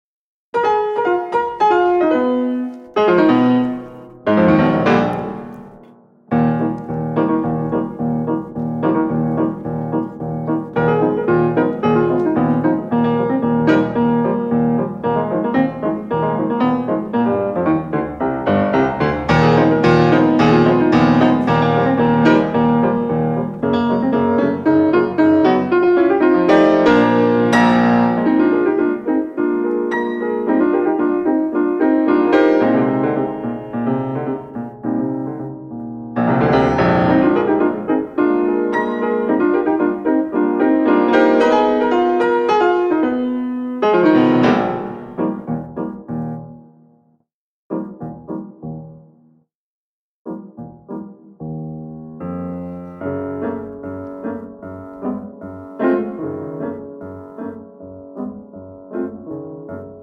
Allegro giusto   3:07